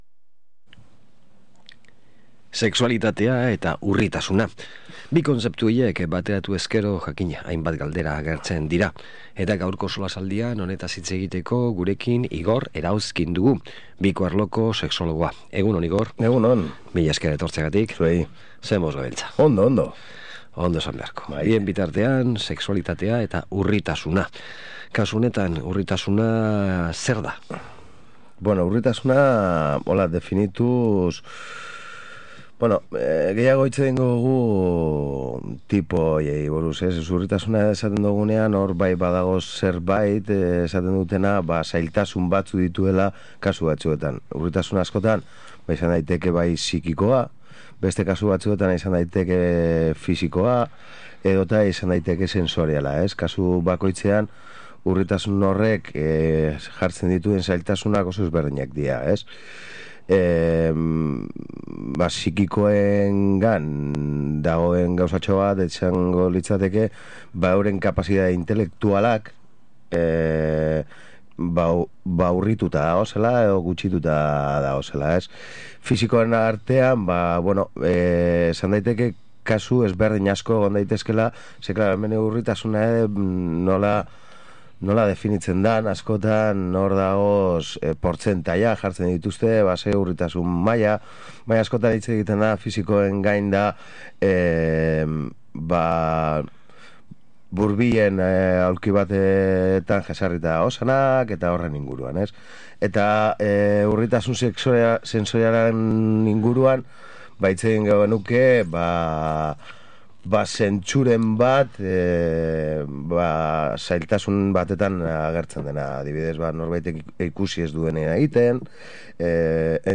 SOLASALDIA: sexualitatea eta urritasuna
solasaldia